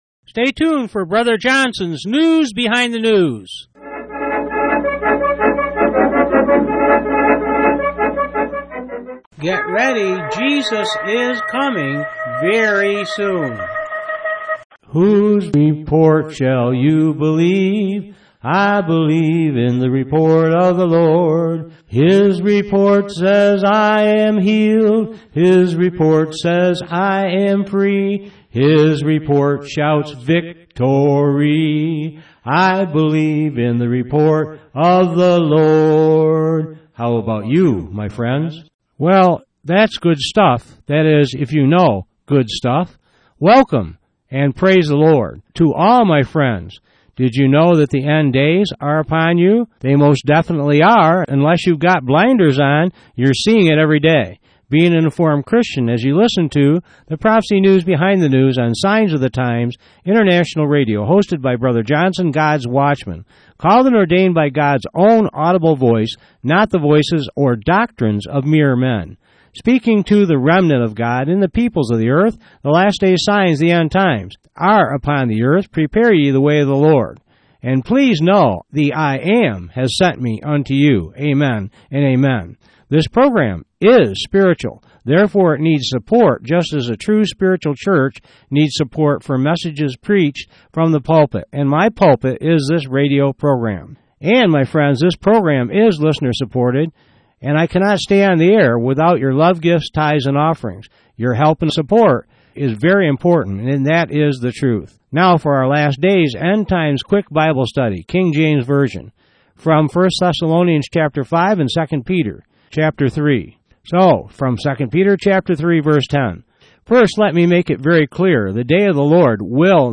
Message Details: WEDNESDAY April 18, 2012 Prophecy Power half hour news